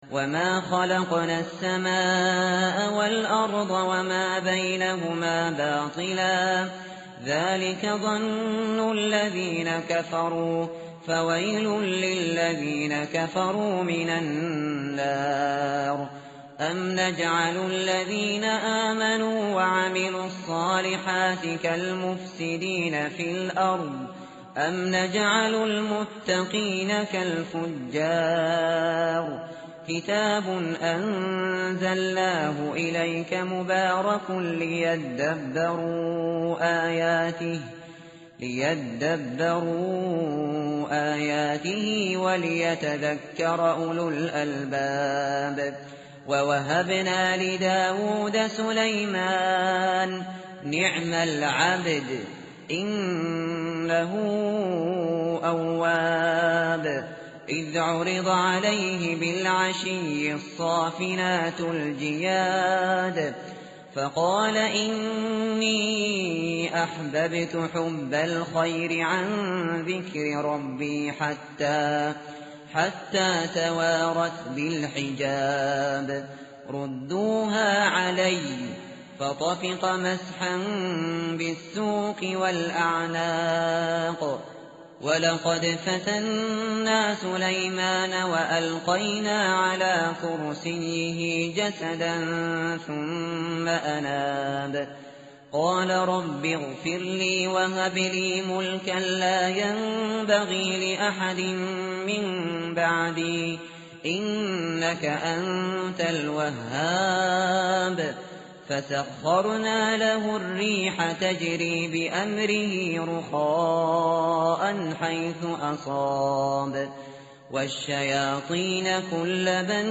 متن قرآن همراه باتلاوت قرآن و ترجمه
tartil_shateri_page_455.mp3